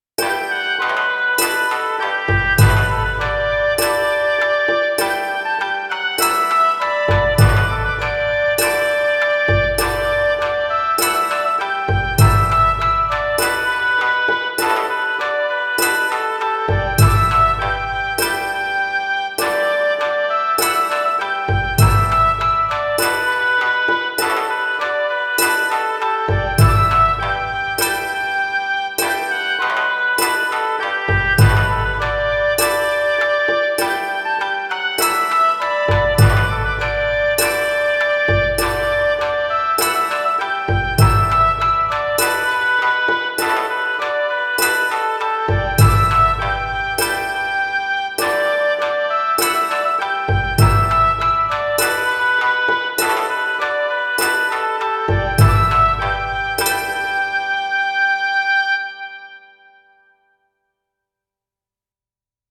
双調バージョンも作ってみました！双調は、Gメジャーのようなものです。「レ」から「ソ」へキーを変えた物と思ってもらえば良いと思います。
聴いていただければ分かるとおり、とってもポップで明るい！！です！
どことなく、中国大陸の雰囲気もあるような・・・
楽器は、篳篥の音をオーボエに、笛をピッコロに、琵琶を三味線にしてます。